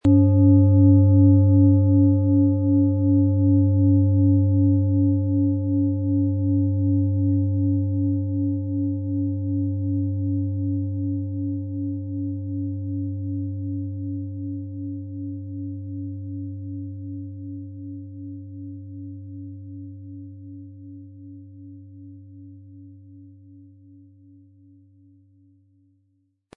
Planetenschale® Stirb- und Werde-Prinzip & Neues beginnen mit Pluto, Ø 24,5 cm, 1400-1500 Gramm inkl. Klöppel
Handgetriebene, tibetanische Planetenklangschale Pluto.
Unter dem Artikel-Bild finden Sie den Original-Klang dieser Schale im Audio-Player - Jetzt reinhören.
Im Lieferumfang enthalten ist ein Schlegel, der die Schale wohlklingend und harmonisch zum Klingen und Schwingen bringt.
MaterialBronze